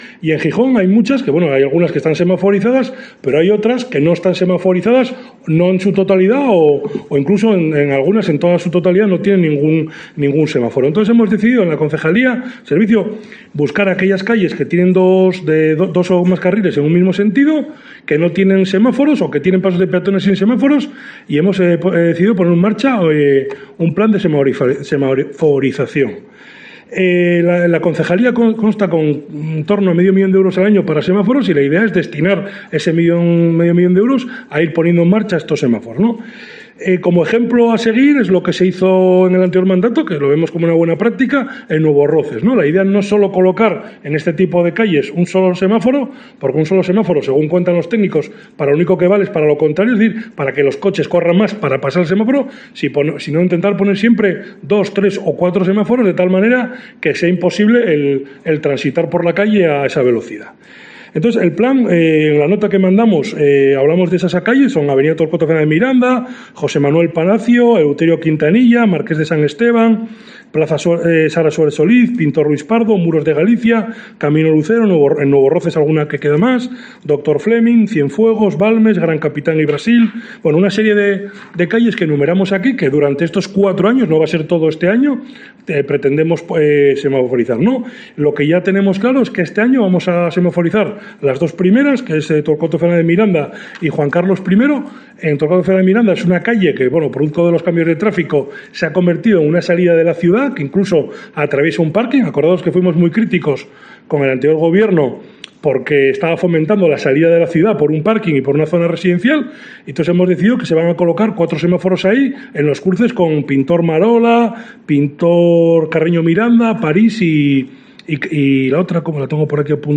El concejal de Tráfico y Movilidad, Pelayo Barcia, explica en COPE Gijón el 'Plan de semaforización'